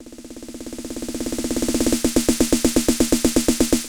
SnareFill3-44S.wav